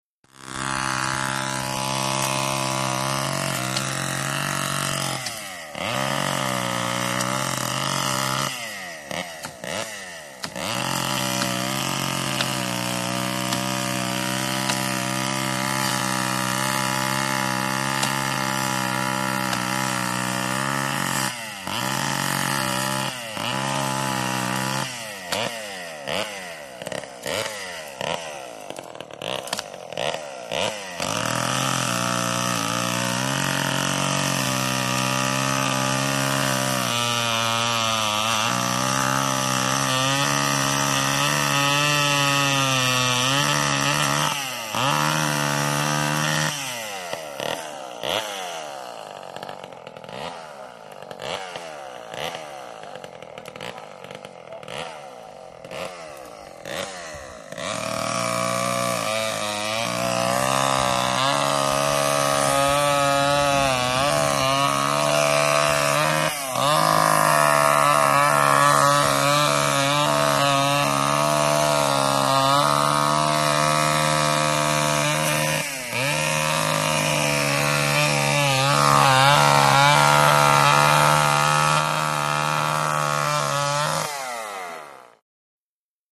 SmlChainSawCutTree PE699701
MACHINES - CONSTRUCTION & FACTORY CHAINSAW: EXT: Small chain saw cutting tree, revs between cutting.